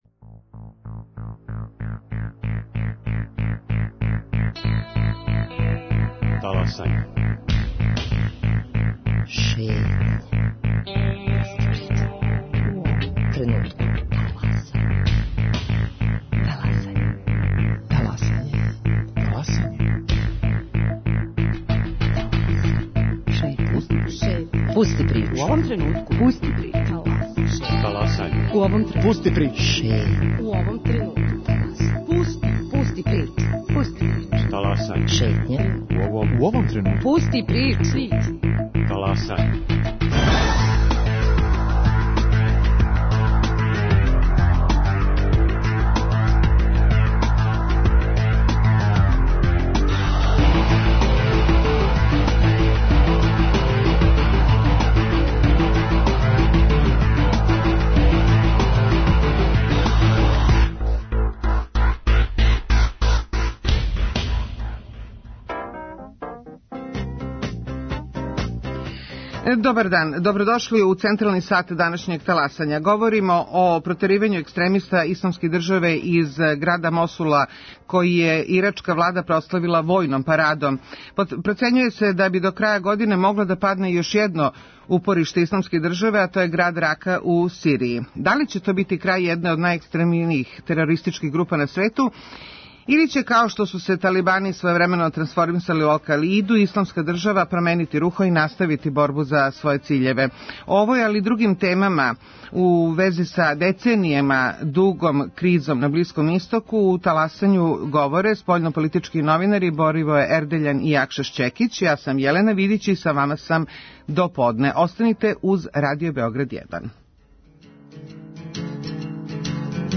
Гости Таласања су спољнополитички новинари